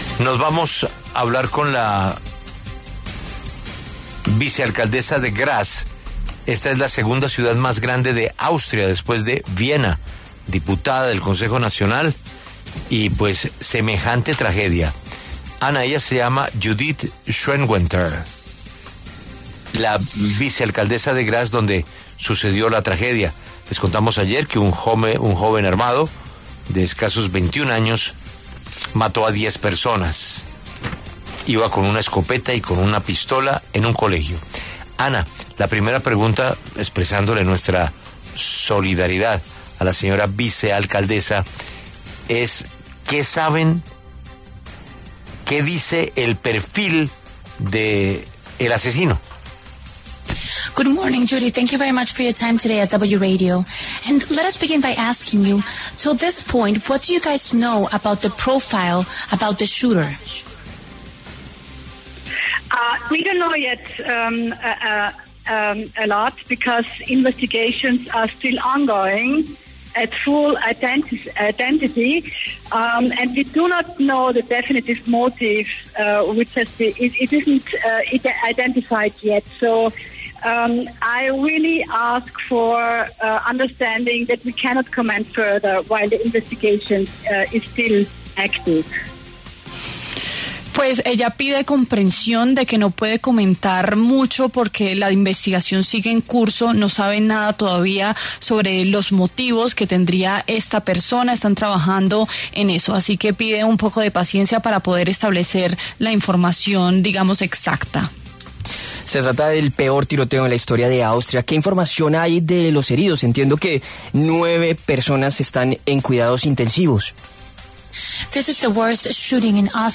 Judith Schwentner, vicealcaldesa de Graz, pasó por los micrófonos de La W para entregar detalles de la masacre que se registró el martes 10 de junio, donde un joven armado de 21 años disparó y mató a 10 personas, entre alumnos y profesores.